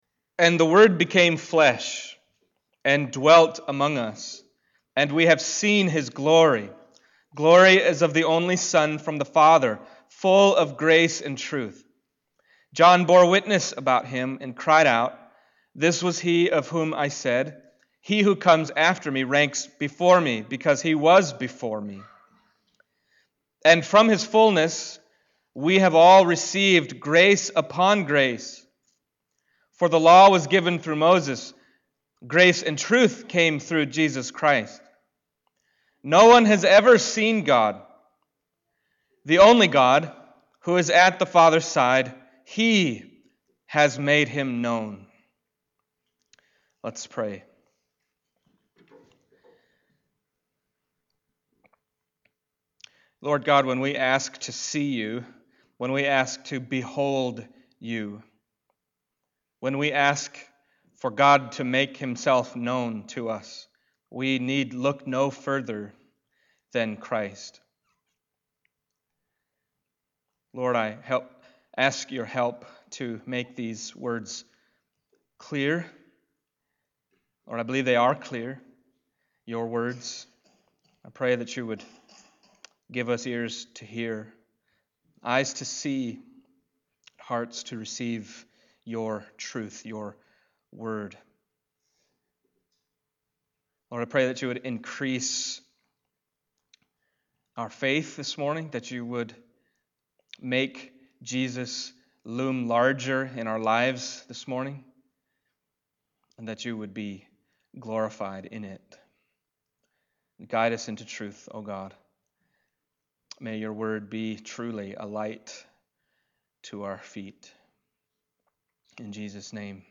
John 1:14-18 Service Type: Sunday Morning John 1:14-18 Bearing Witness That Jesus is the Son of God »